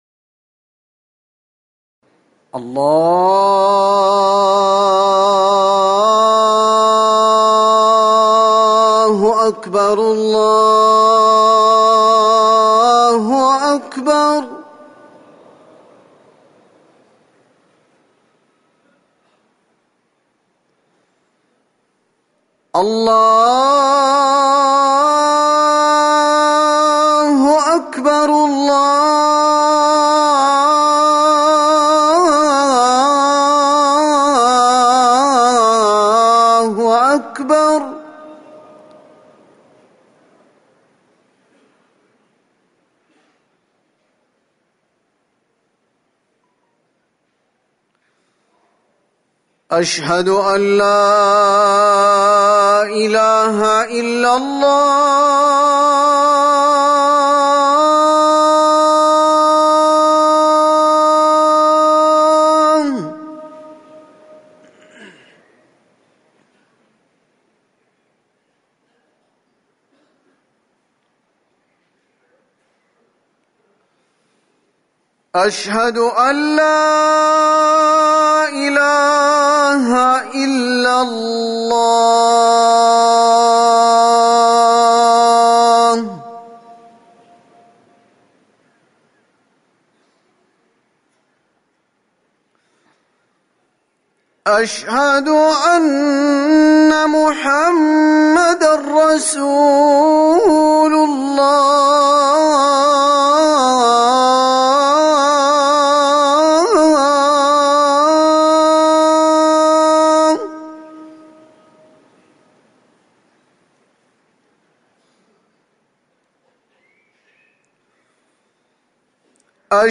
أذان العصر
تاريخ النشر ١٦ صفر ١٤٤١ هـ المكان: المسجد النبوي الشيخ